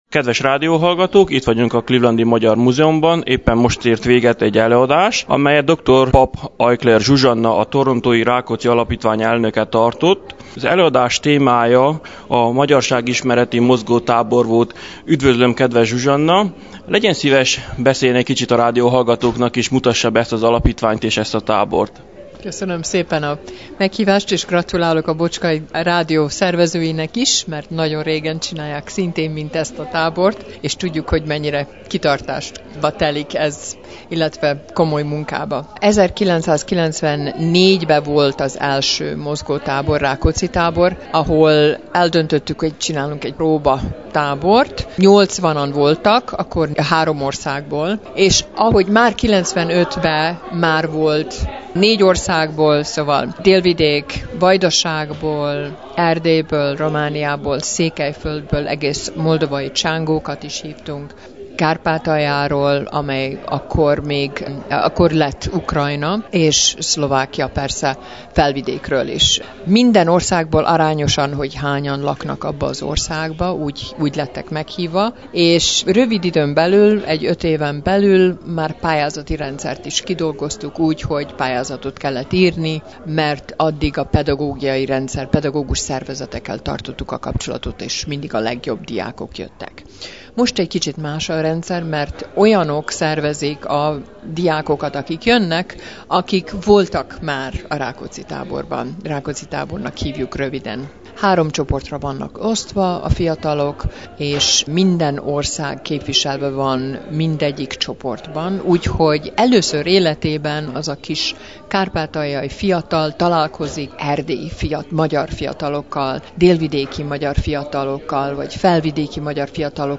előadást tartott a Clevelandi Magyar Múzeumban